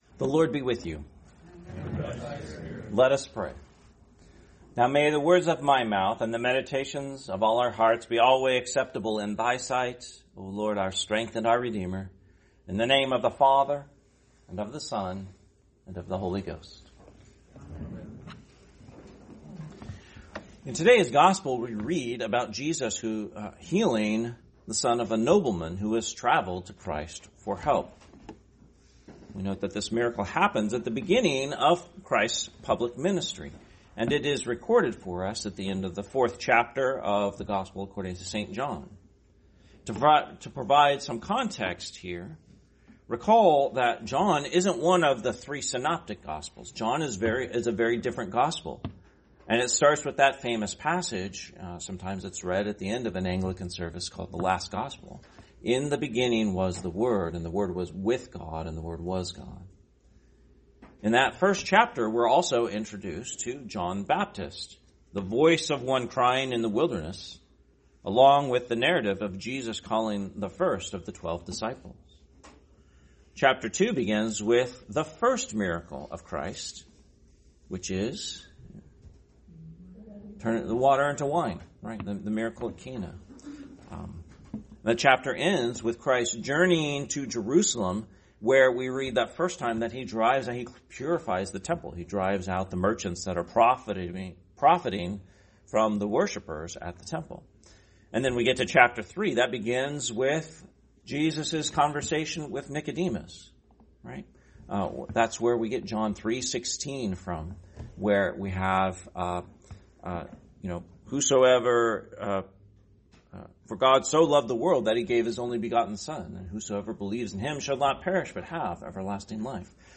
Sermon, 21st Sunday after Trinity, 2025 – Christ the King Anglican Church